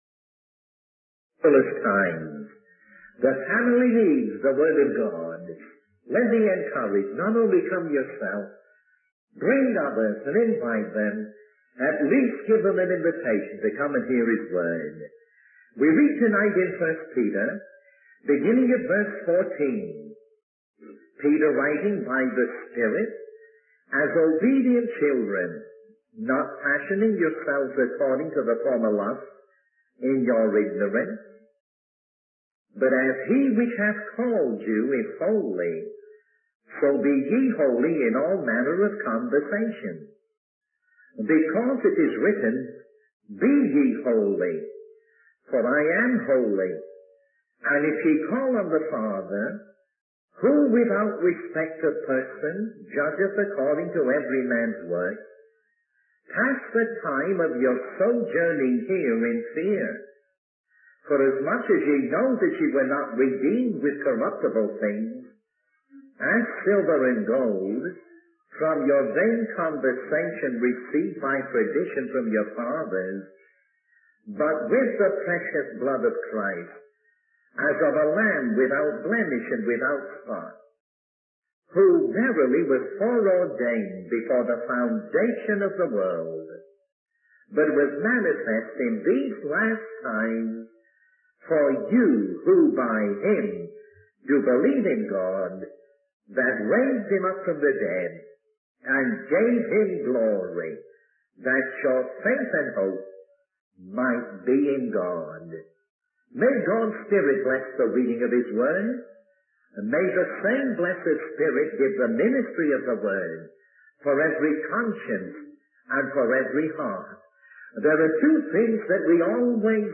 In this sermon, the preacher emphasizes the importance of being conscious that God is judging us every day. He encourages believers to be obedient children and to live holy lives, as the Father calls His children to obedience.